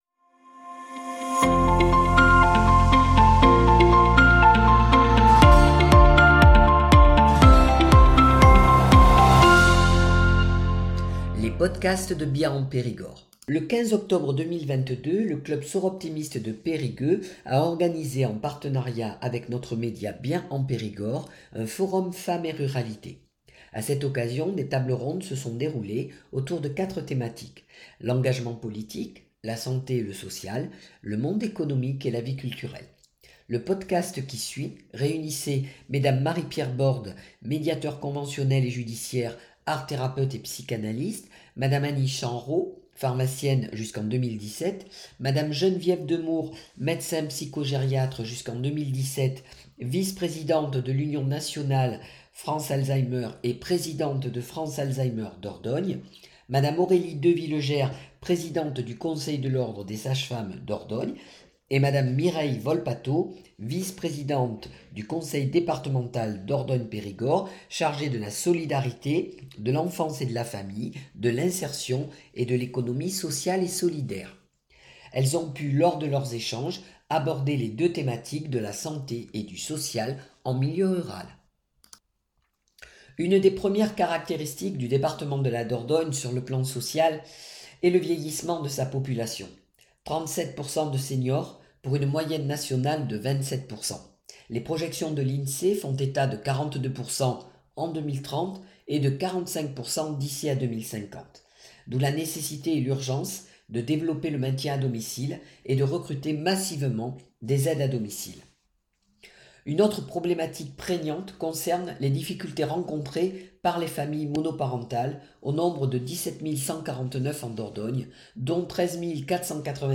Les problématiques sociales et de santé, accentuées en milieu rural, fragilisent plus particulièrement les femmes. Ces deux thématiques, ainsi que les initiatives et solutions mises en place pour les réduire, ont été abordées lors du Forum Femmes et ruralité organisé par le club Soroptimist de Périgueux, en partenariat avec notre média.